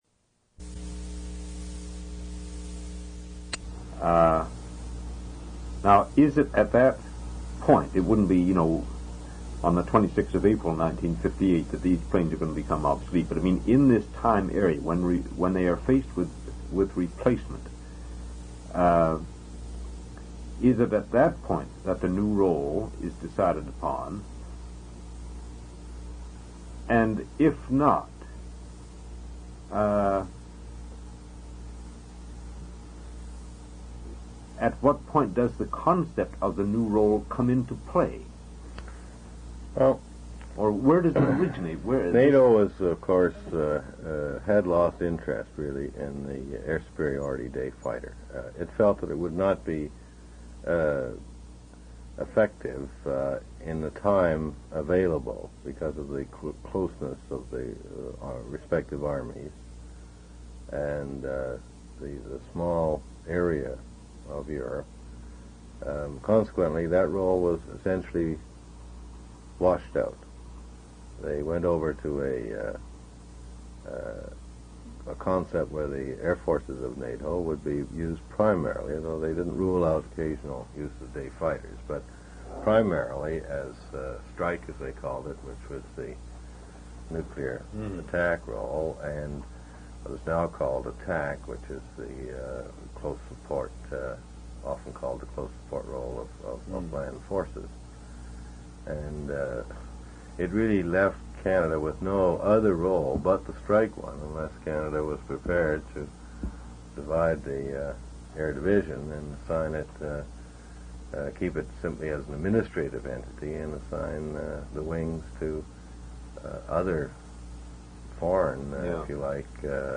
Two original sound tape reels (ca. 2 hours, 35 min.) : 1 7/8 ips, 2 track, mono.